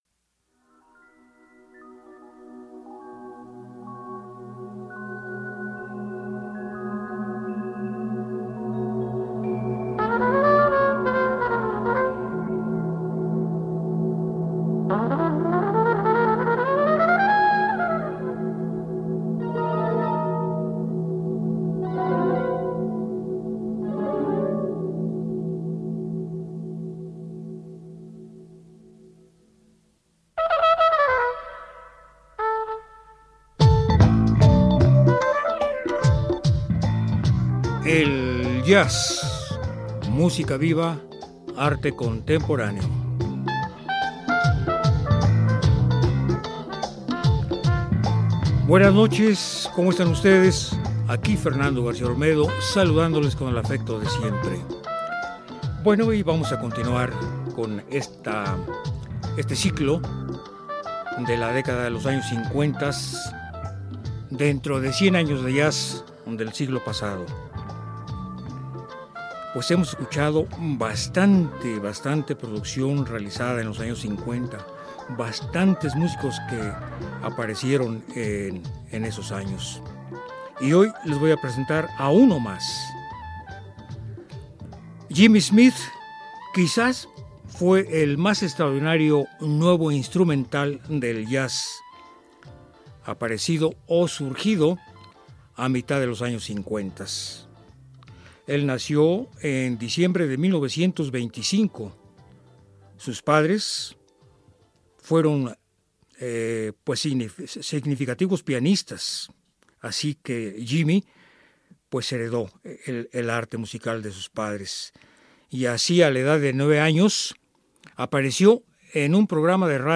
experto en el órgano Hammond.